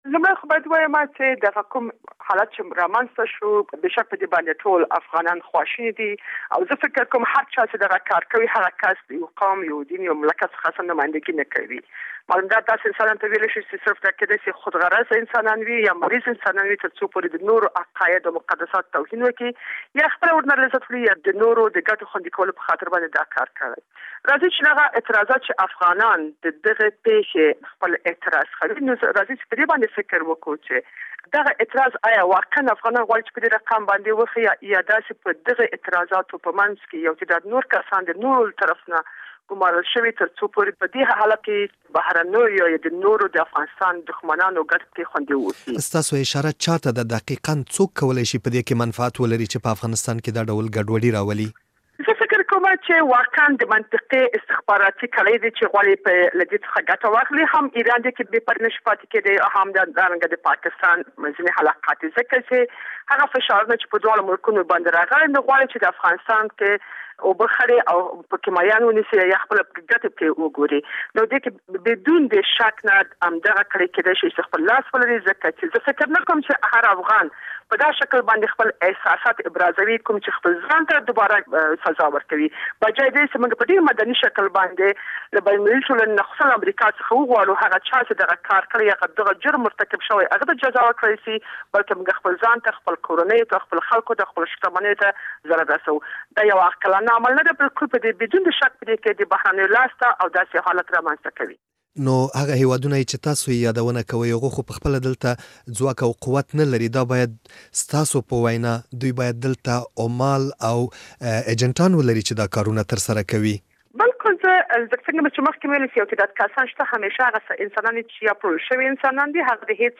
د وروستیو مظاهرو په اړه له شینکۍ کړوخېل سره مرکه